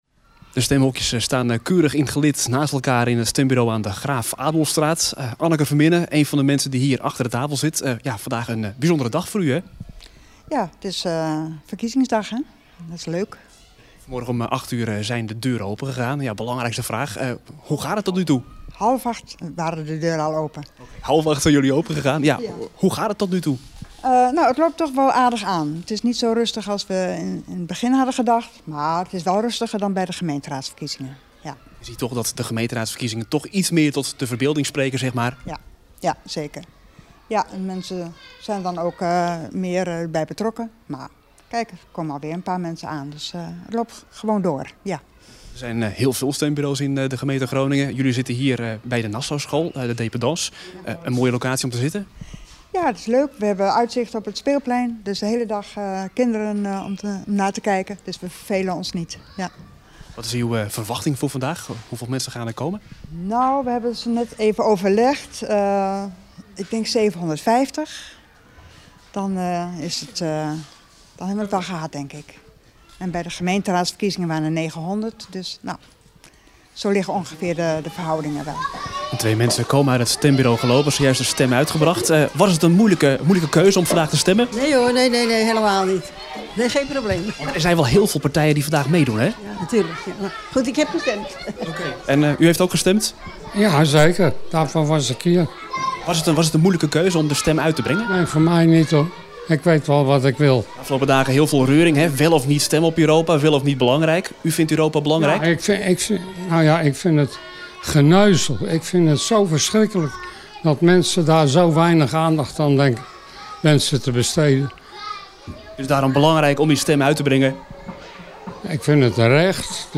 Eén van de locaties waar gestemd kan worden is de dependance van de Nassauschool aan de Graaf Adolfstraat in de Oranjewijk.
Een vrouw van middelbare leeftijd heeft zojuist haar stemformulier in de bus gedaan.